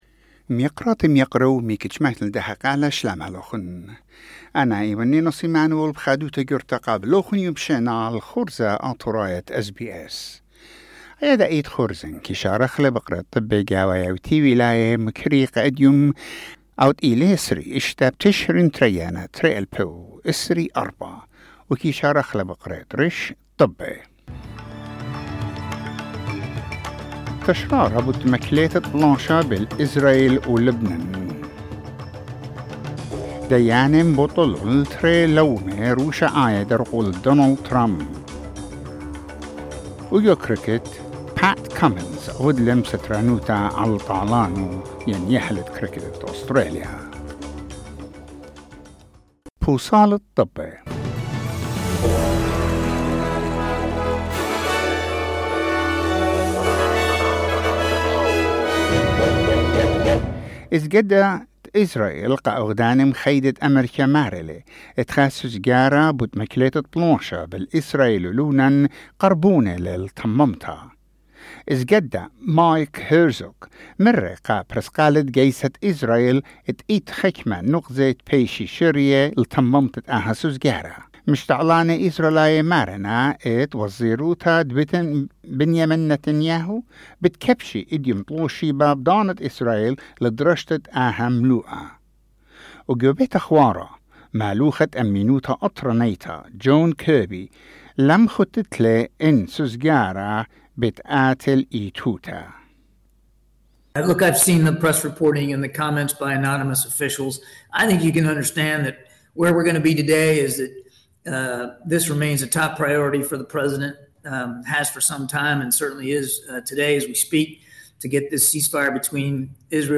SBS Assyrian news bulletin: 26 November 2024